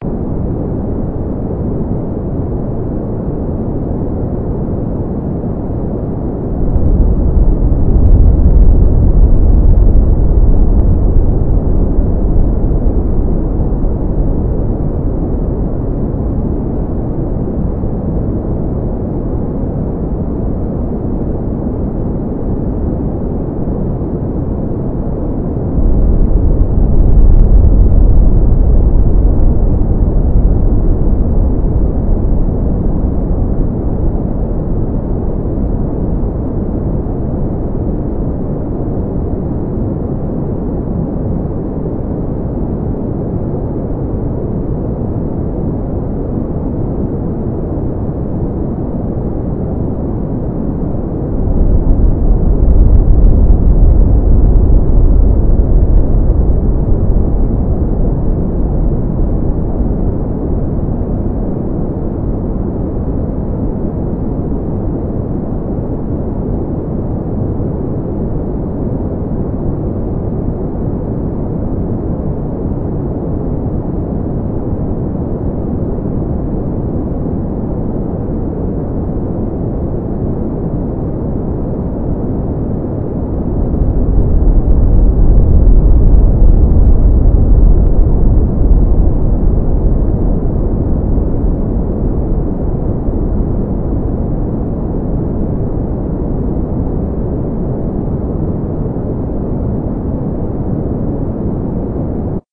rainthunderloop.ogg